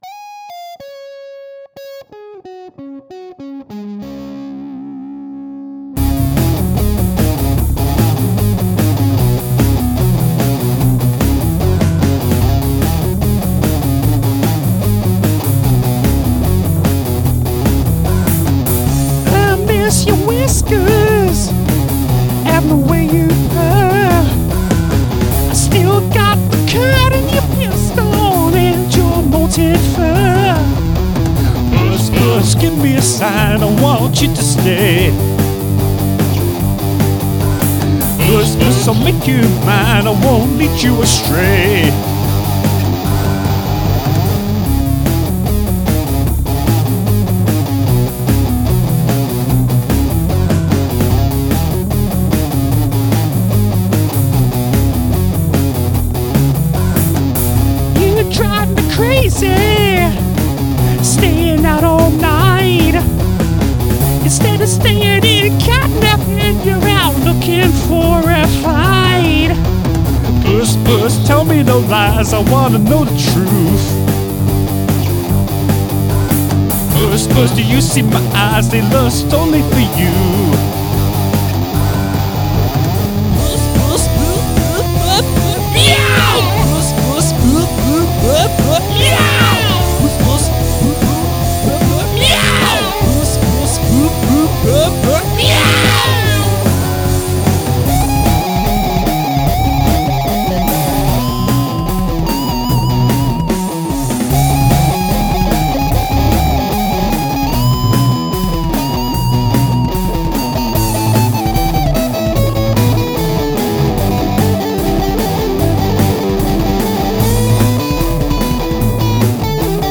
A little funk is added to the rock stew
guitar solo